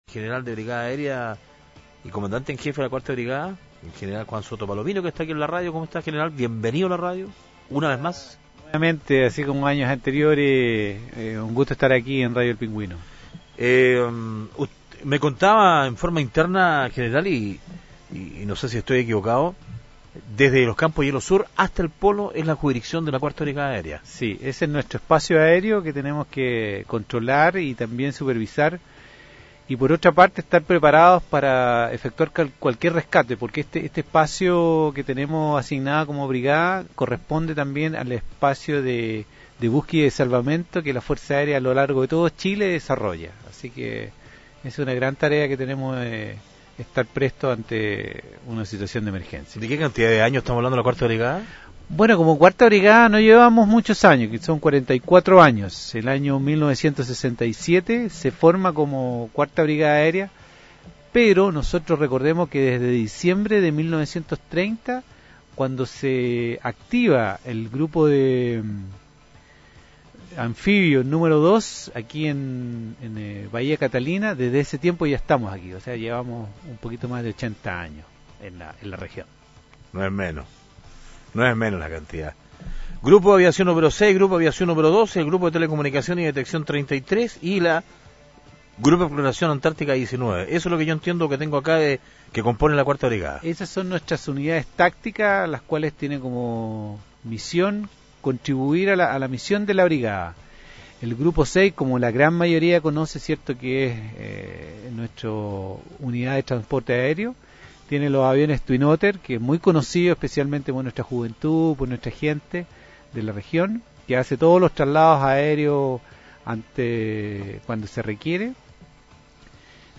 Entrevistas de Pingüino Radio - Diario El Pingüino - Punta Arenas, Chile